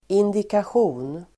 Ladda ner uttalet
indikation substantiv, indication Uttal: [indikasj'o:n] Böjningar: indikationen, indikationer Synonymer: antydan, tecken Definition: tecken, antydan indication substantiv, tecken , kännetecken , symptom , indikation